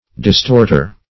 Distorter \Dis*tort"er\, n. One who, or that which, distorts.